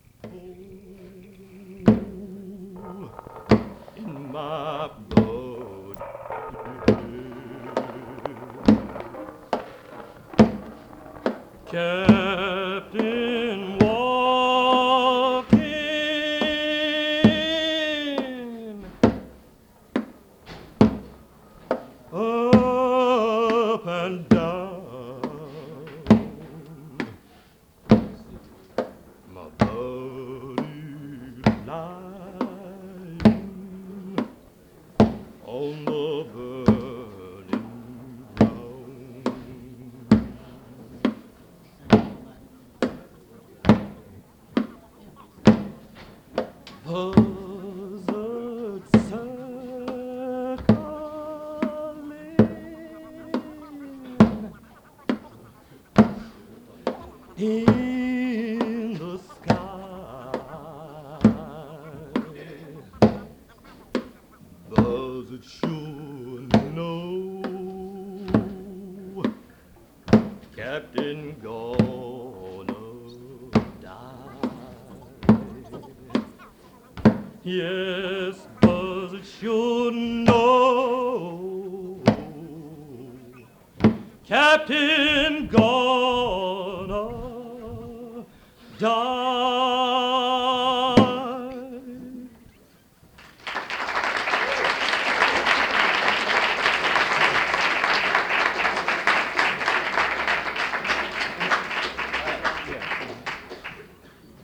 Live at the 1961 Indian Neck Folk Festival
We have managed to acquire digital copies of those tapes and have edited them into individual tracks which you can hear here for the first time in 60 years.